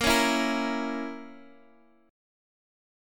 A#dim chord